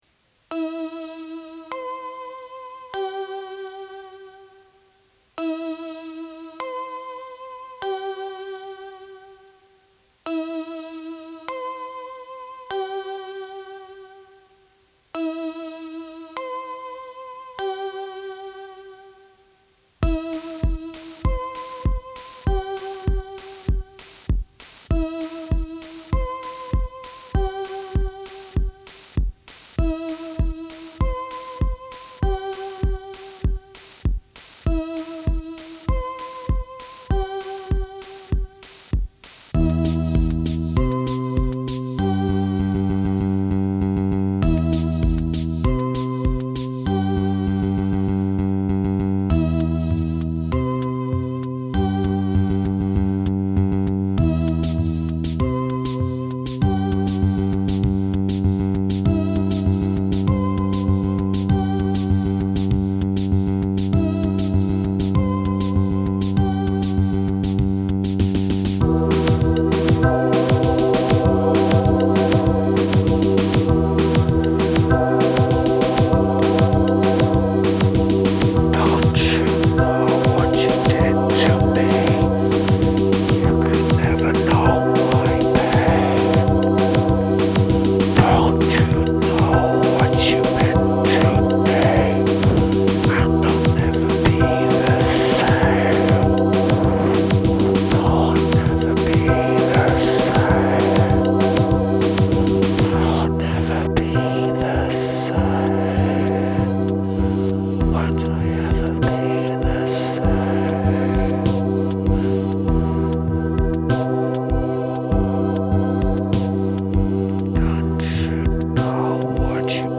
Early MIDI and sample experiments.
Timing on my vocals is dreadful!